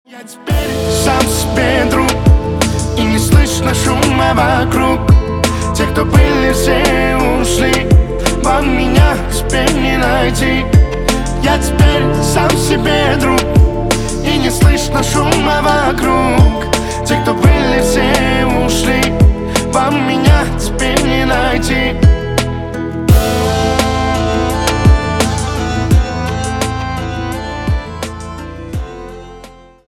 Поп Музыка # кавказские # спокойные